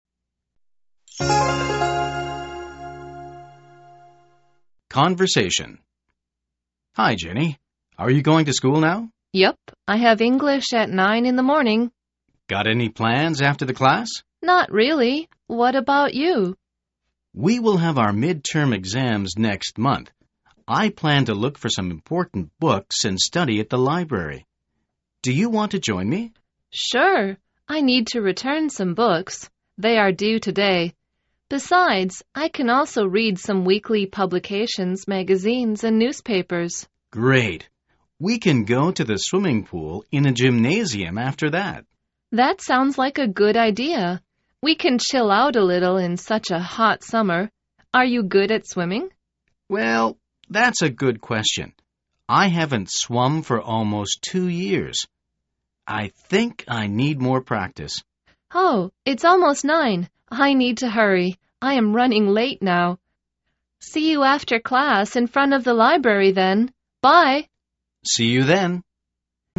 口语会话